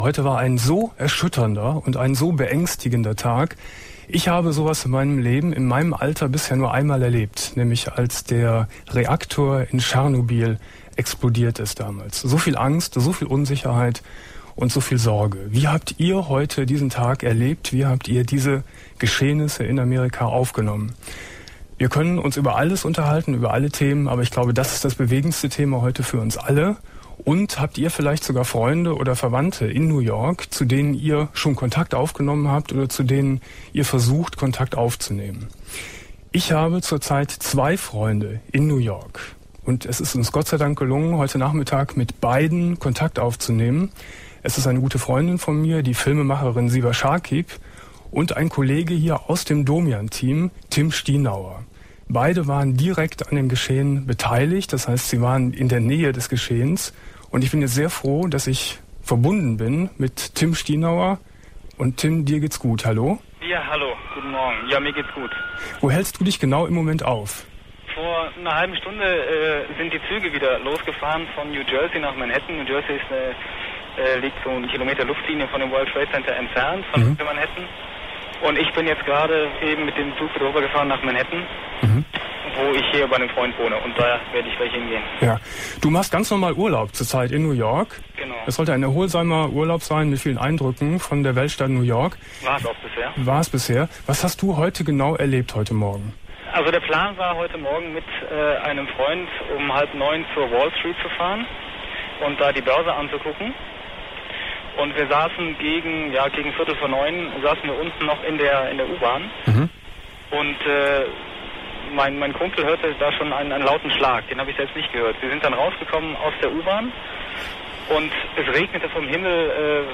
12.09.2001 Domian Thema: Offen (Terroranschläge) ~ Domian Talkradio Archiv Podcast